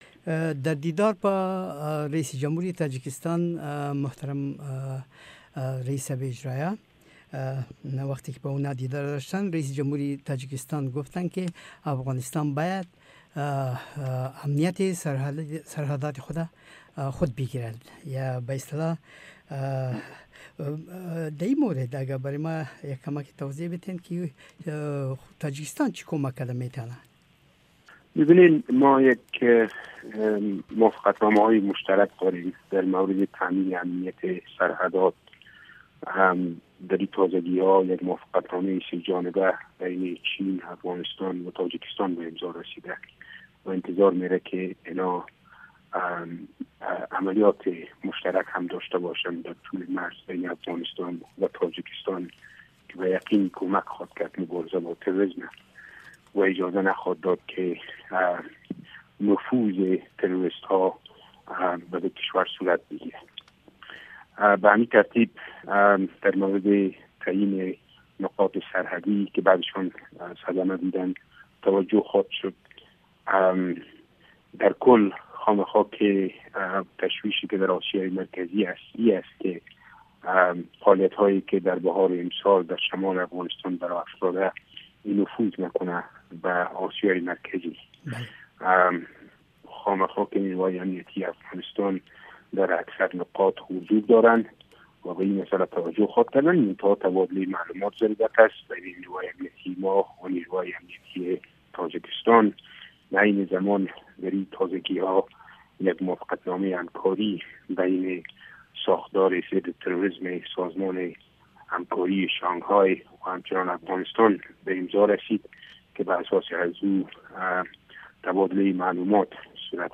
صحبت آقای صیقل